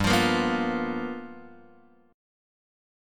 G 7th Sharp 9th
G7#9 chord {3 x 3 3 0 1} chord